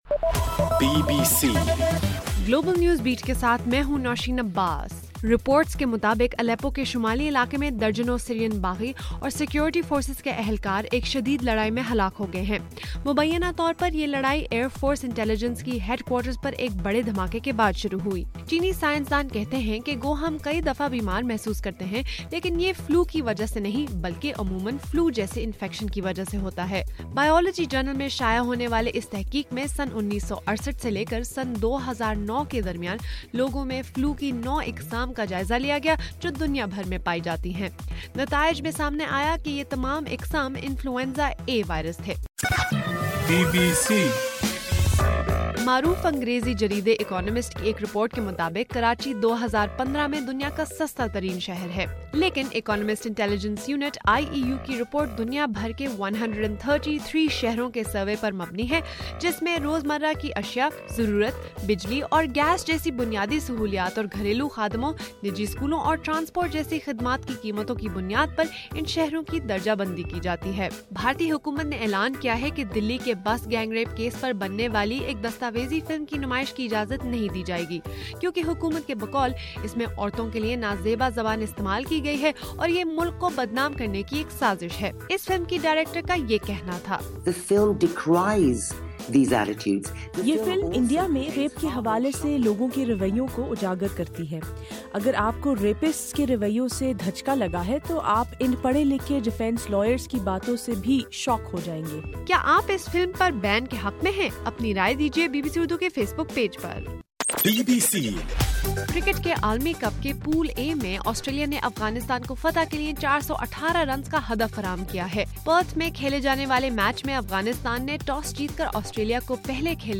مارچ 5: صبح 1 بجے کا گلوبل نیوز بیٹ بُلیٹن